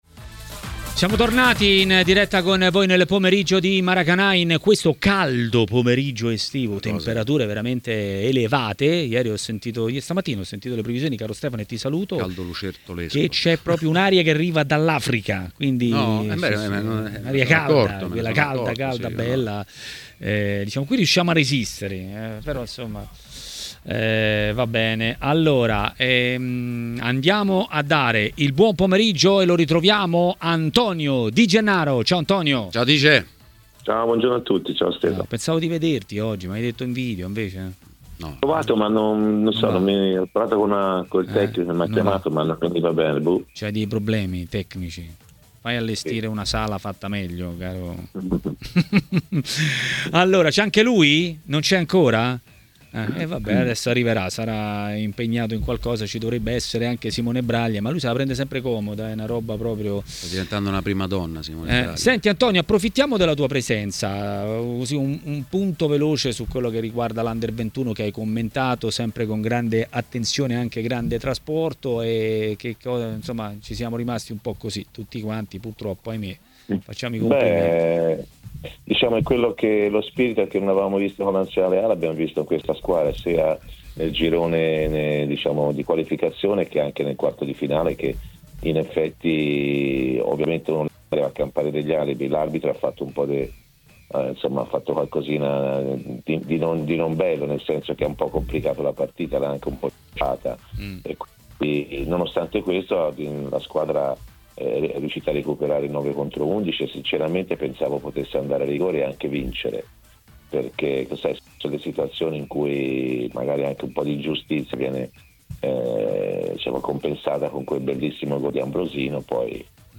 Antonio Di Gennaro, ex calciatore e commentatore tv, ha parlato dei temi del giorno a TMW Radio, durante Maracanà.